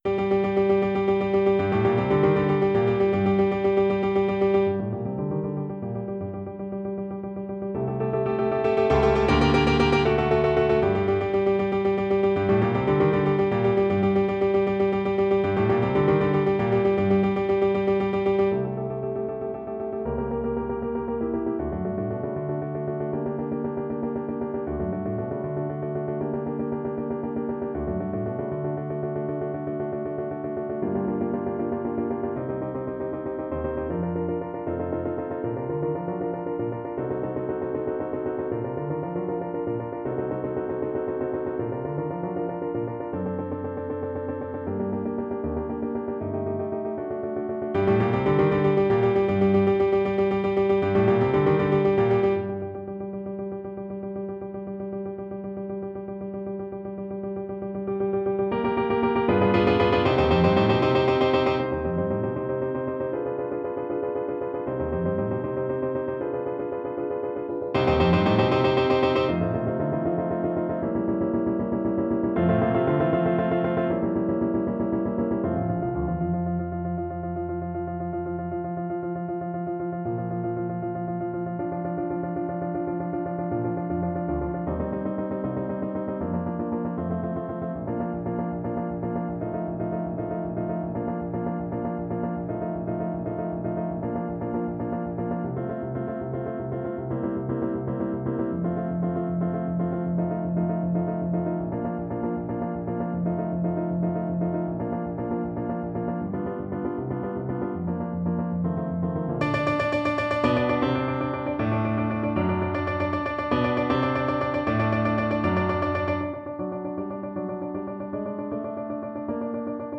Hier findest du sowohl den Demosong als auch das Playback als mp3- sowie ggf. ergänzendes Unterrichtsmaterial.